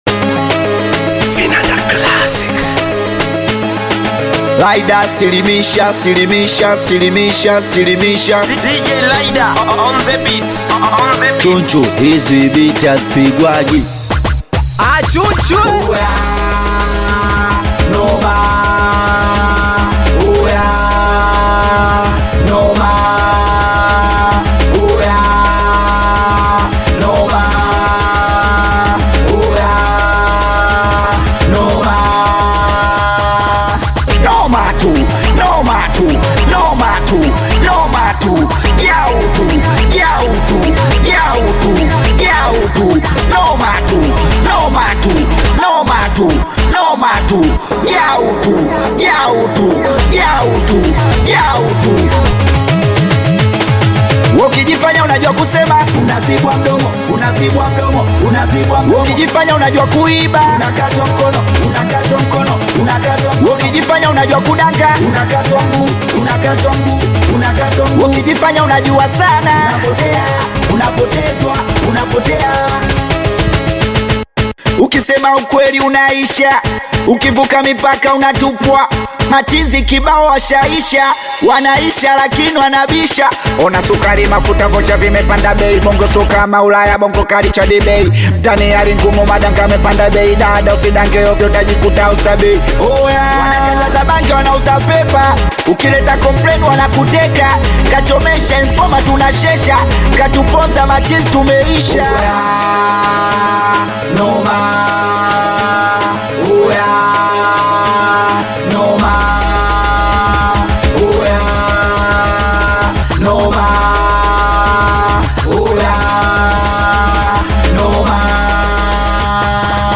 Singeli music track
Tanzanian Bongo Flava Singeli artist